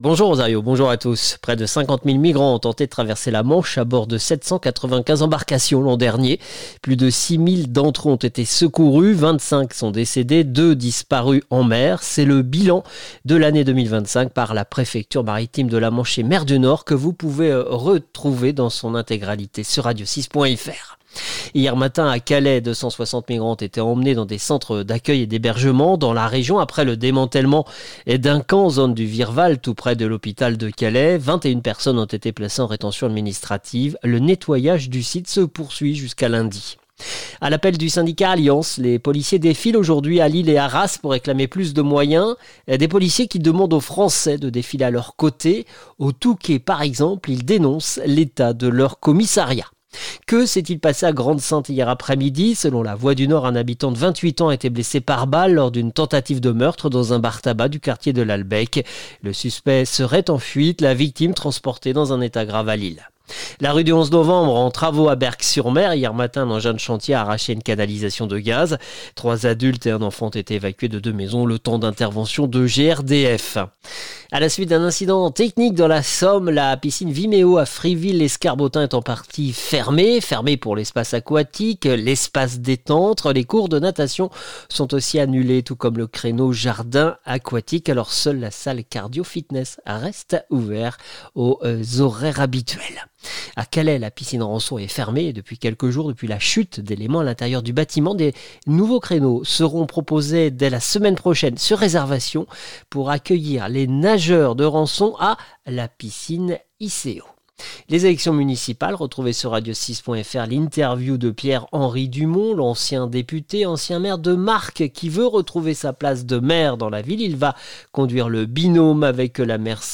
Le journal du samedi 31 janvier 2026